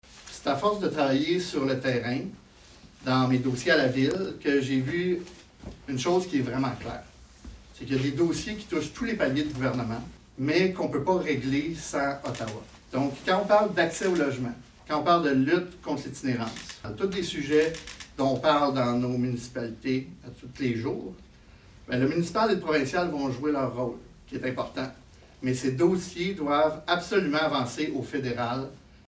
En mêlée de presse, le candidat libéral a indiqué que ce saut en politique fédérale faisait partie de ses plans d’avenir et fort de son expérience municipale, il sent qu’il peut faire une différence.